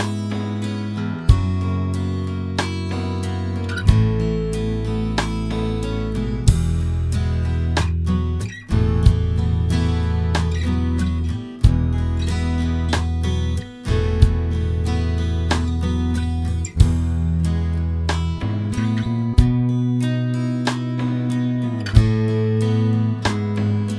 no sax Pop (1980s) 4:22 Buy £1.50